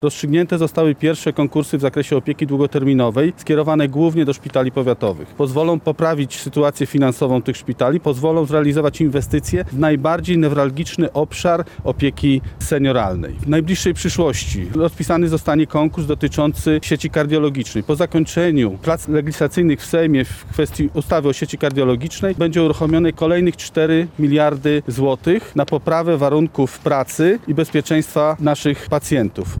Znaczenie środków z KPO podkreślał poseł Platformy Obywatelskiej Krzysztof Bojarski.